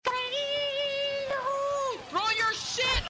Lirik saying Shortyyy. 140 Ansichten.